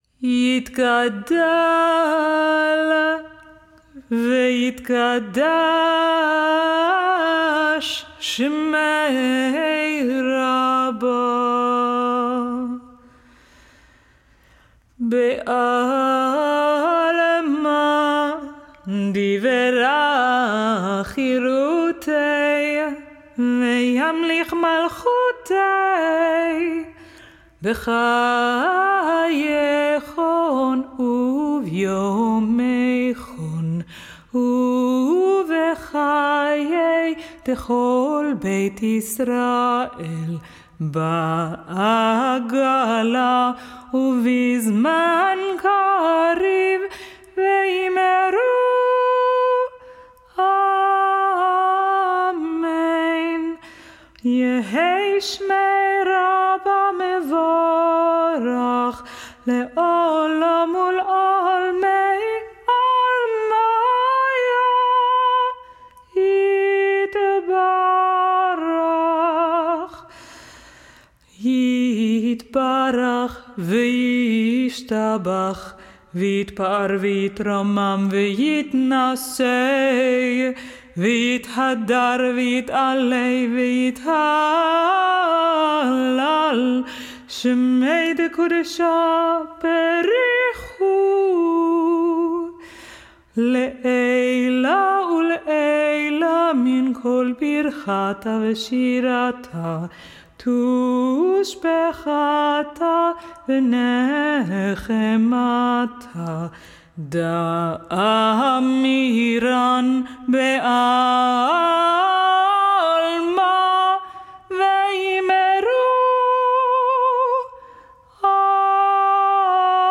Reflections on my career as a cantorial soloist:
Days of Awe liturgical recordings
Avinu Malkeinu - traditional